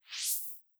LittleSwoosh4.wav